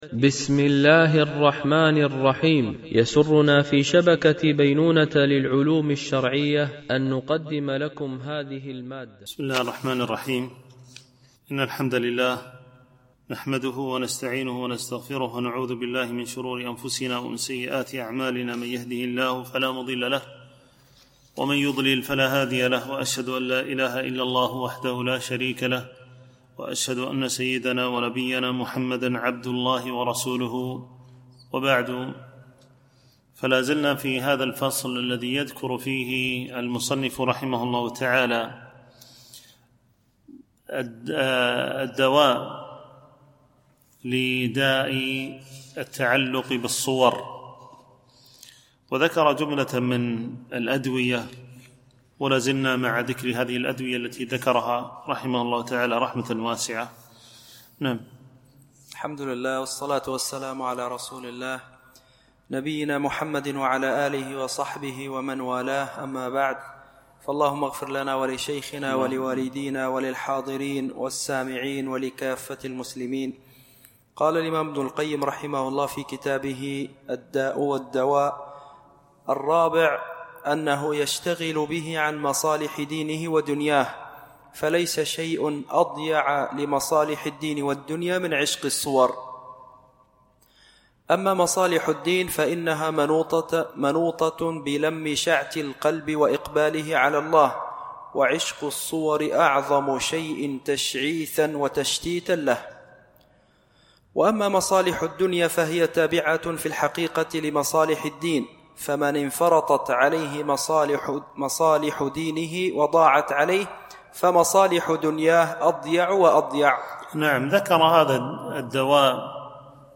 شرح كتاب الداء والدواء ـ الدرس 53